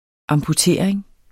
Udtale [ ɑmpuˈteˀɐ̯eŋ ]